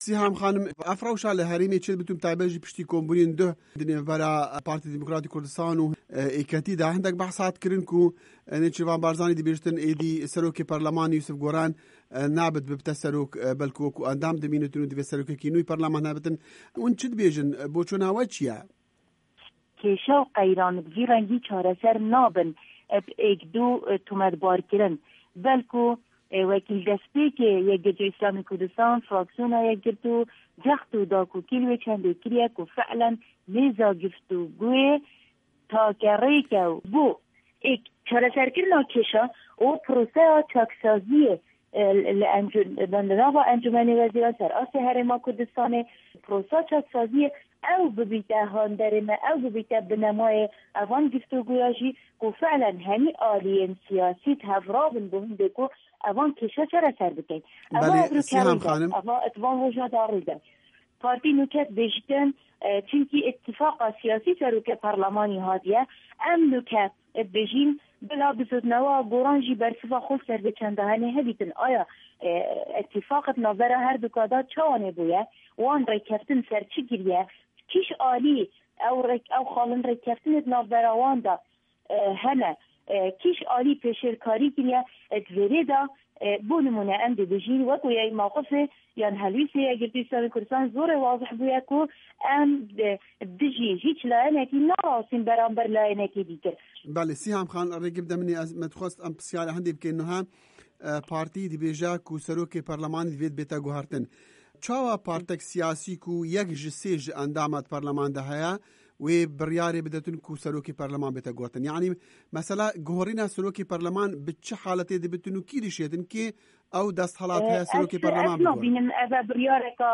Siham Omer Qadir, endama Yekgirtuya Îslamî di parlemana herêmê de gote Dengê Amerîka, posta serokatiya perleman bi rêkeftina Partî û Gorran çêbûye divê ew vegerin wê rêkeftinê.